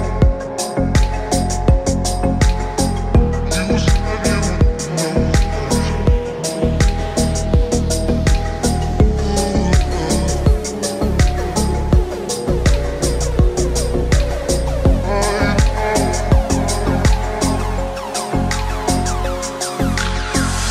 Category: English Ringtones